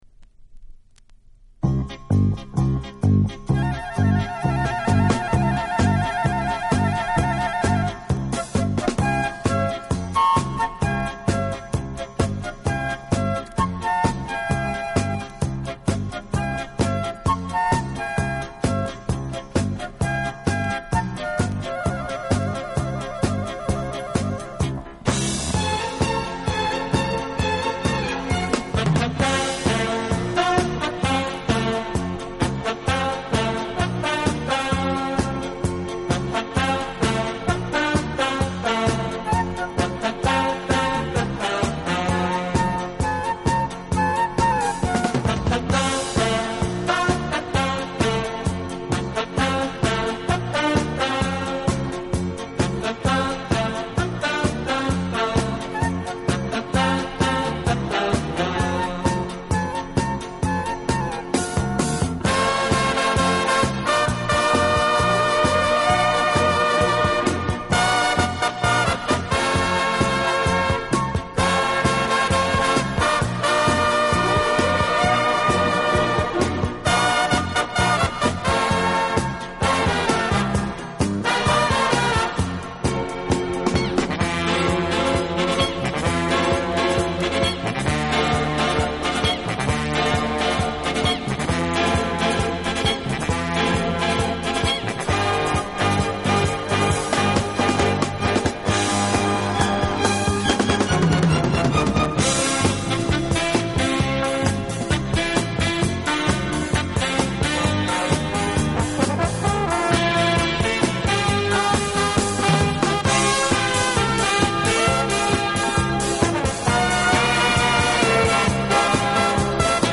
【轻音乐】
Genre: Instrumental